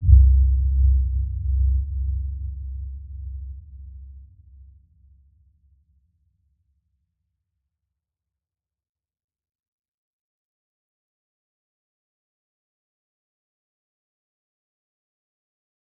Dark-Soft-Impact-C2-mf.wav